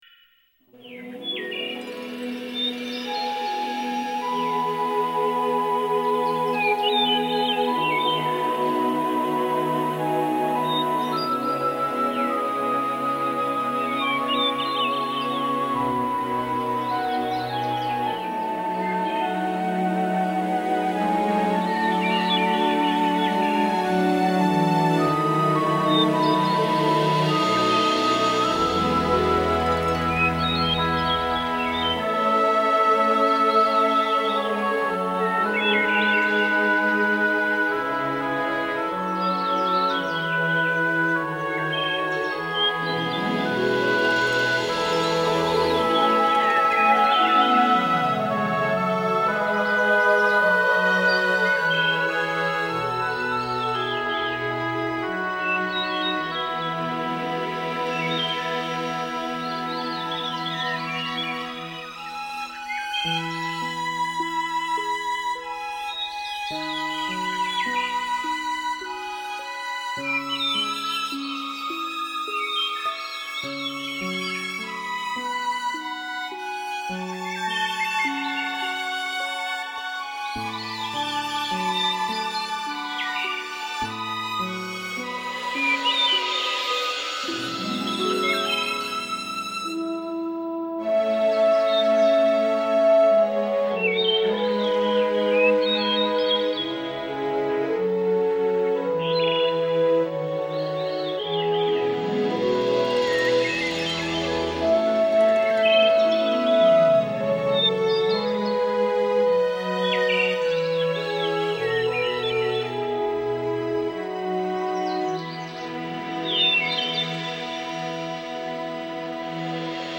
Baby Lullabies - Brahms - LullabyClassical Version.mp3